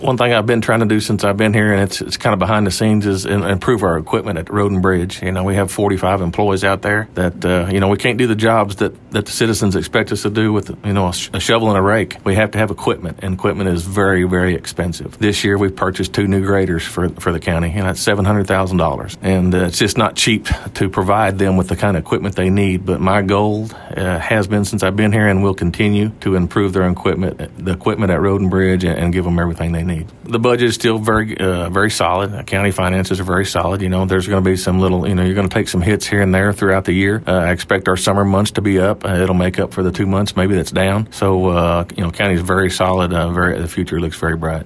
KTLO News caught up with the Judge to discuss the upcoming campaign and his motives for seeking re-election.